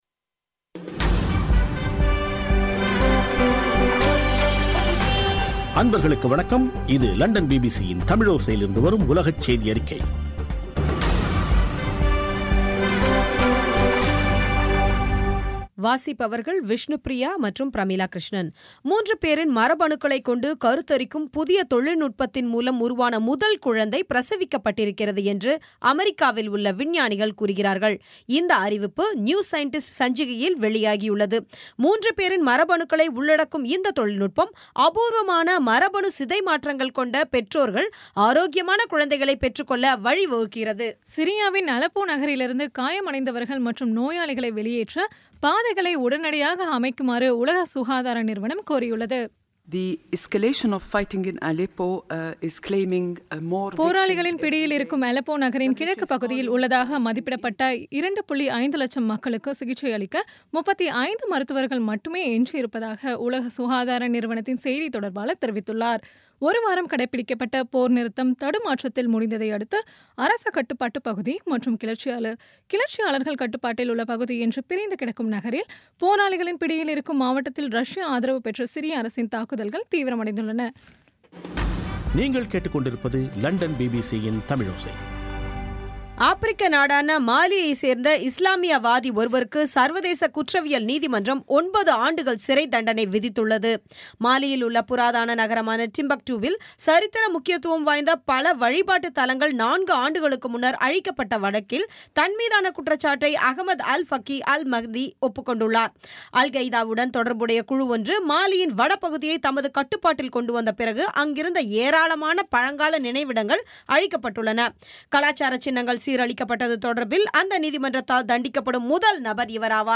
பி பி சி தமிழோசை செய்தியறிக்கை (27/09/2016)